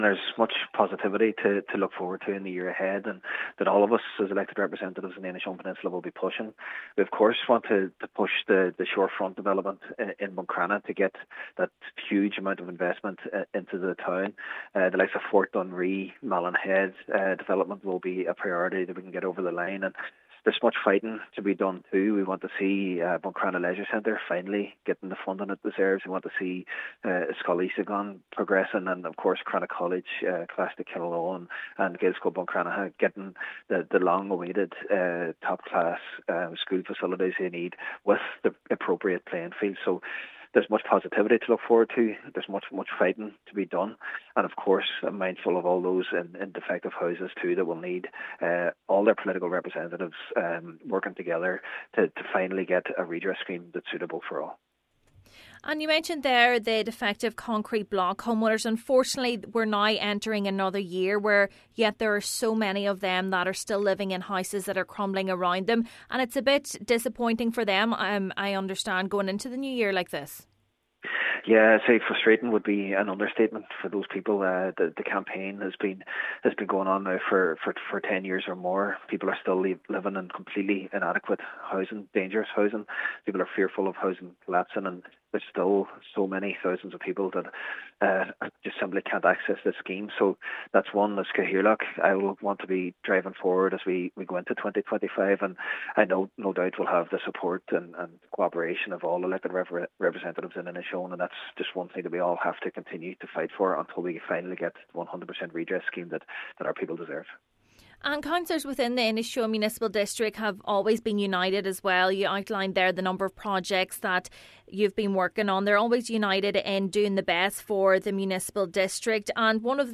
Councillor Jack Murray says the need for redress for defective concrete block homeowners will also be high on his agenda in the coming months: